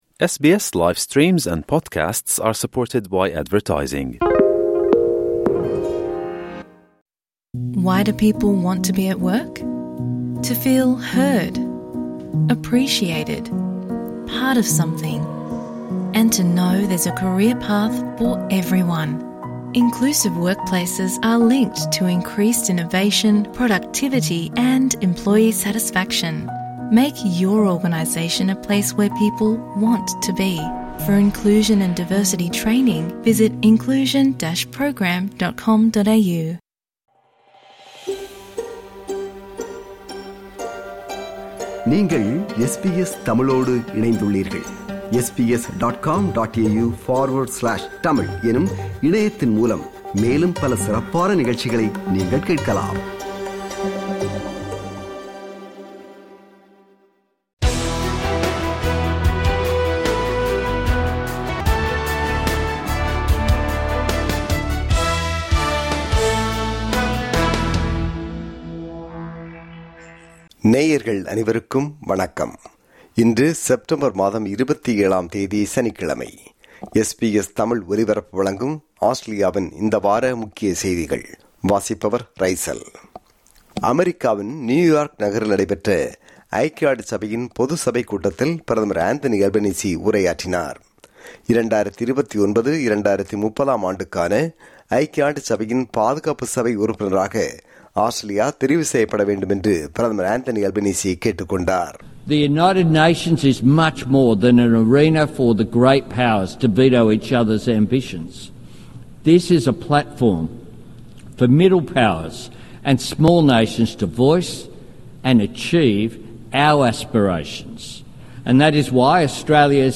SBS Studios